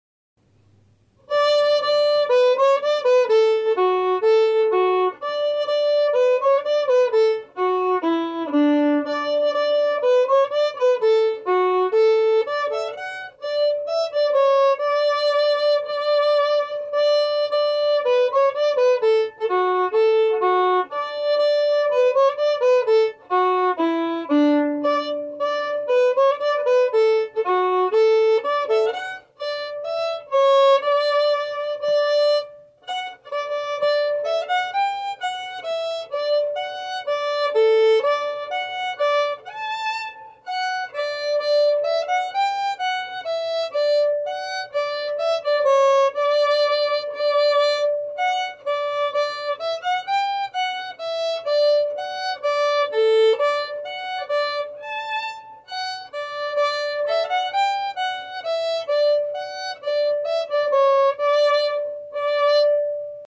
Key: D
Form: Polka
Played slowly for learning
M: 2/4
Genre/Style: Irish Polka